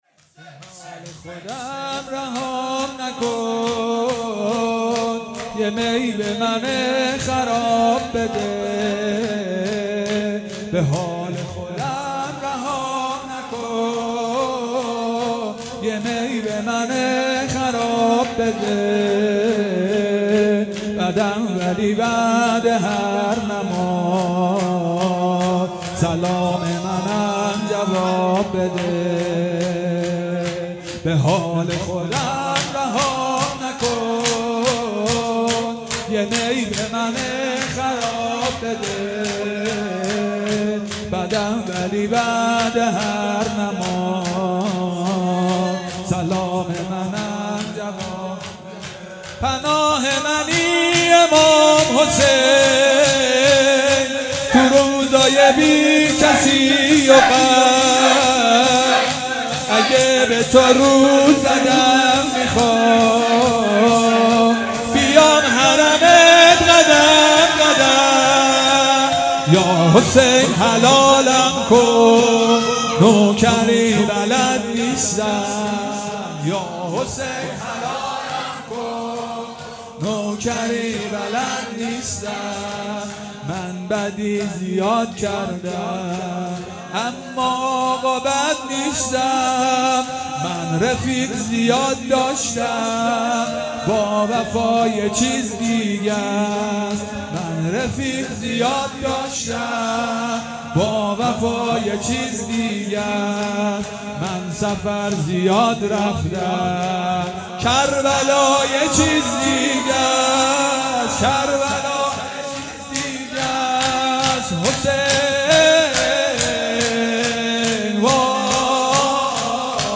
شب پنجم محرم الحرام 1441